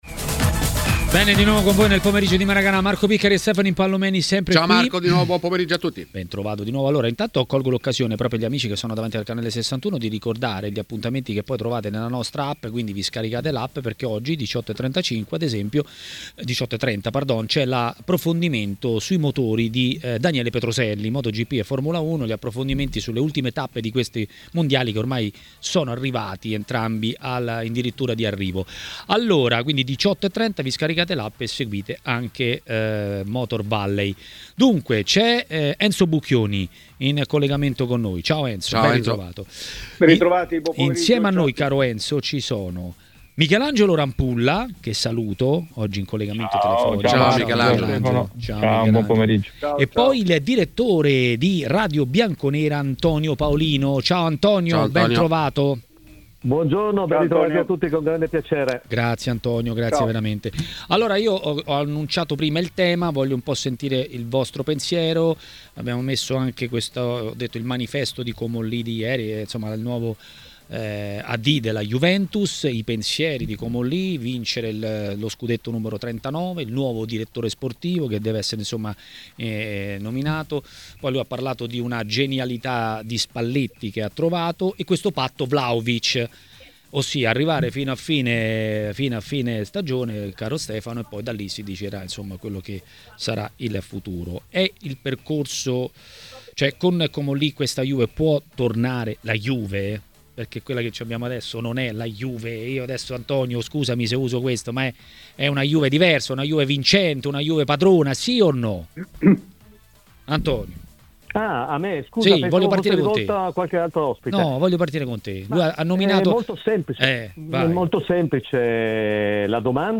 L'ex portiere Michelangelo Rampulla è intervenuto nel corso di Maracanà, trasmissione di TMW Radio.